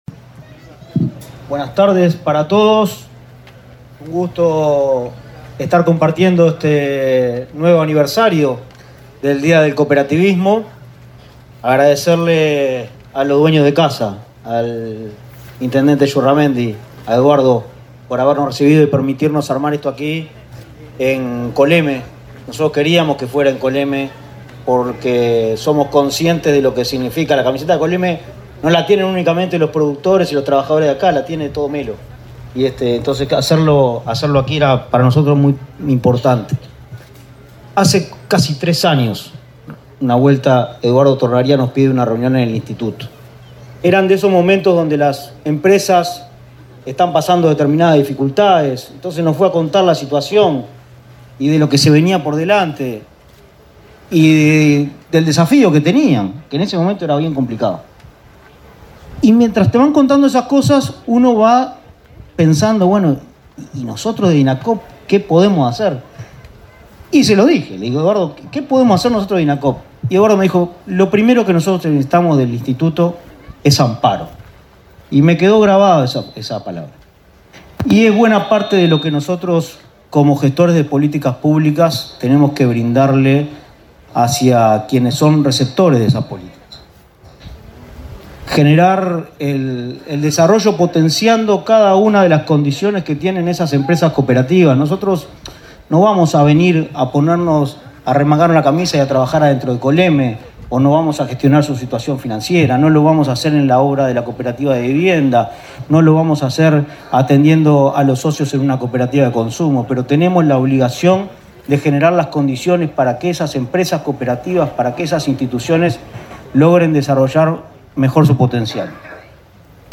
Palabra de autoridades en acto de cooperativismo
El presidente de Inacoop, Martín Fernández; el ministro de Trabajo, Pablo Mieres; y el secretario de Presidencia, Álvaro Delgado, participaron en Melo